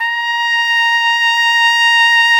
Index of /90_sSampleCDs/Roland L-CD702/VOL-2/BRS_Piccolo Tpt/BRS_Picc.Tp 2 St